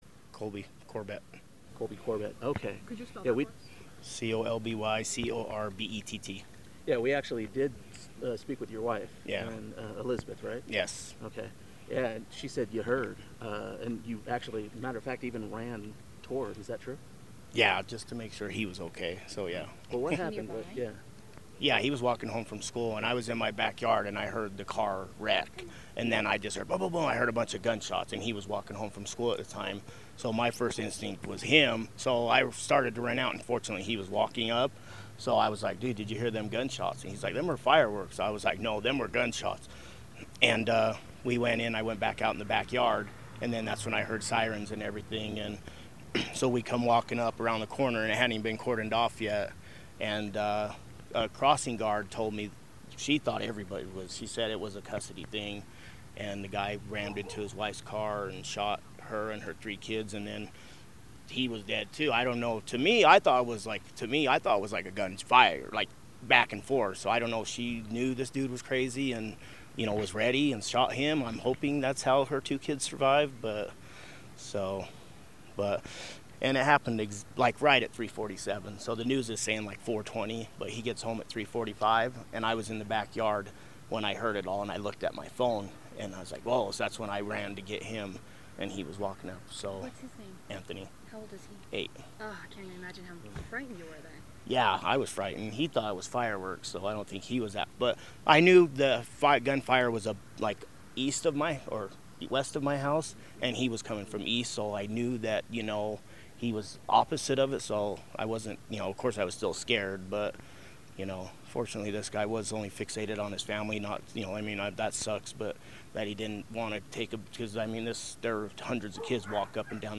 Raw interview